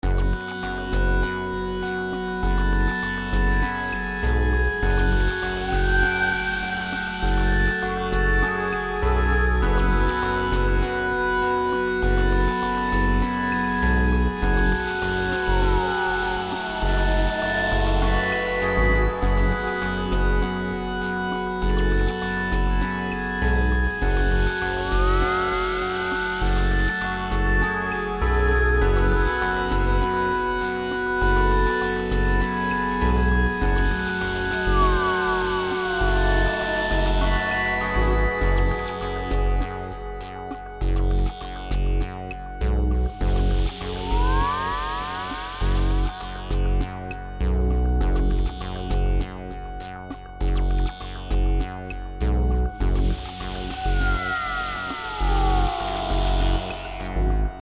This time it's something very moody, still nothing one would dare to call a track (it's just under 1 minute).